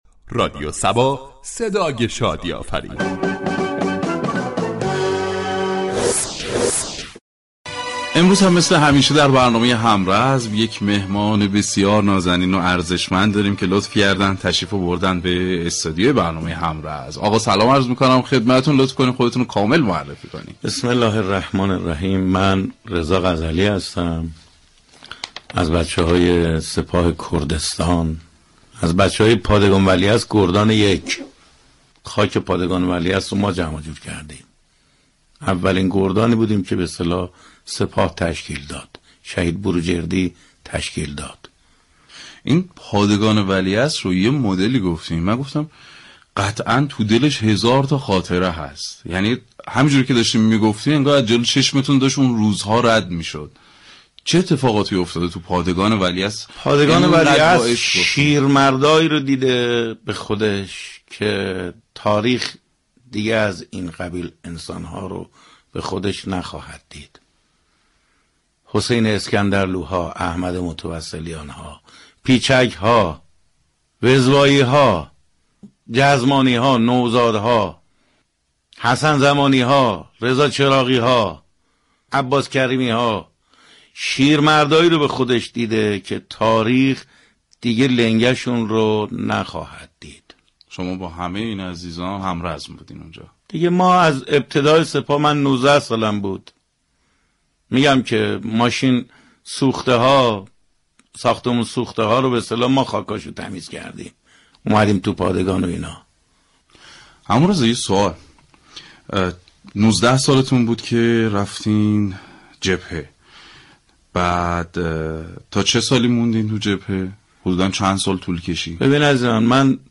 "همرزم" ویژه برنامه ای است ،با محوریت گفتگو با یكی از جانبازان یا ایثارگرانی كه فرصت حضور در جبهه‌های جنگ را داشته اند.